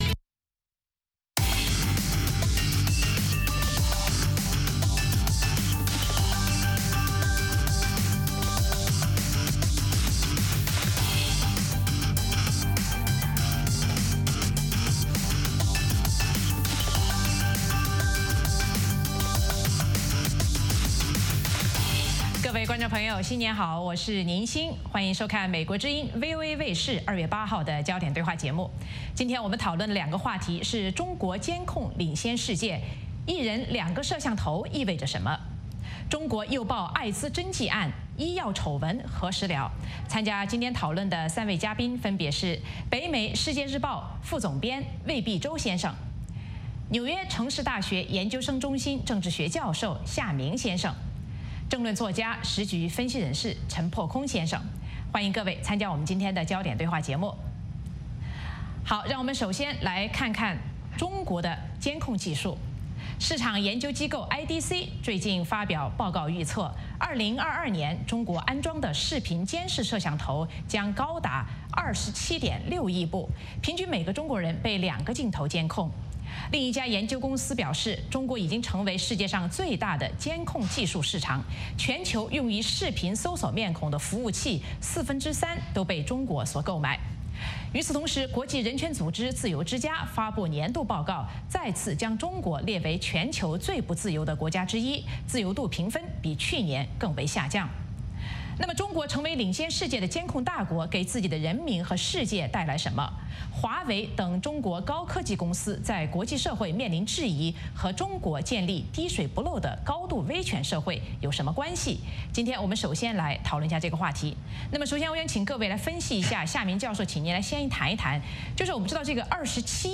《焦点对话》节目追踪国际大事、聚焦时事热点。邀请多位嘉宾对新闻事件进行分析、解读和评论。或针锋相对、或侃侃而谈。